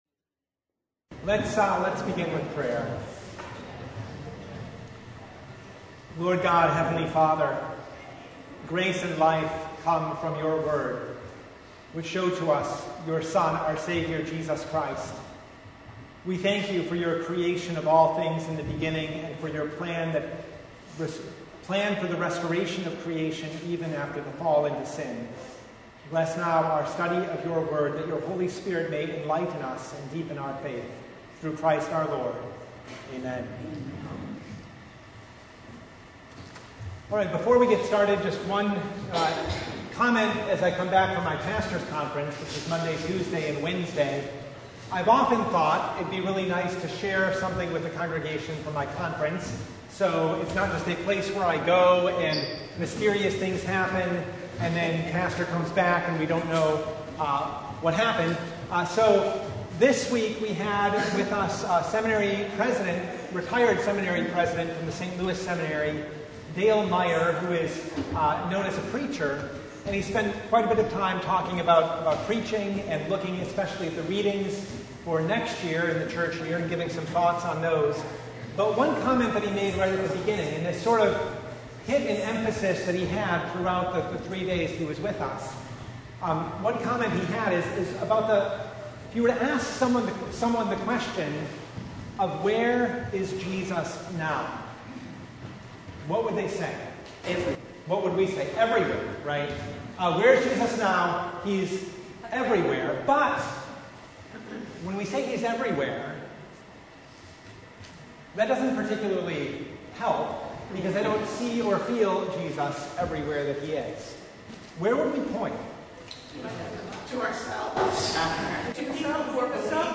Genesis 2:15-25 Service Type: Bible Hour Topics: Bible Study « The Festival of the Reformation